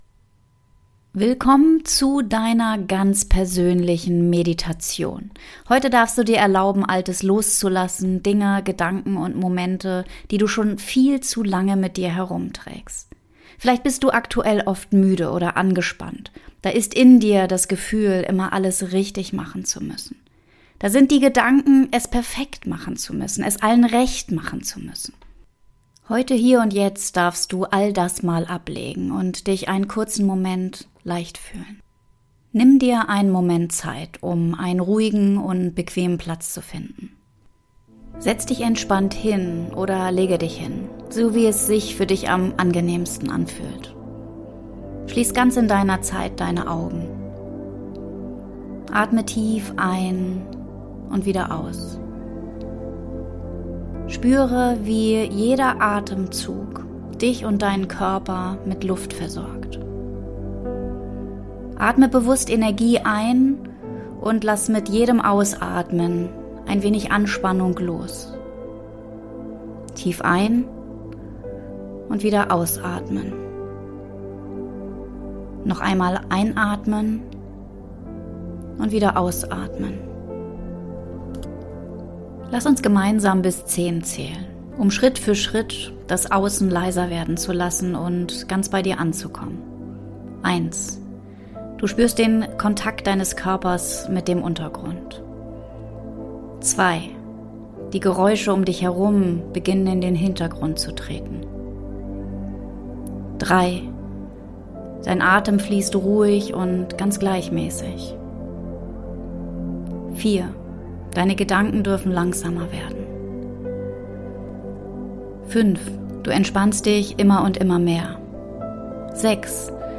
Beschreibung vor 1 Jahr In dieser Episode führe ich dich durch eine Meditation, die dir hilft, innezuhalten, Blockaden zu erkennen und loszulassen.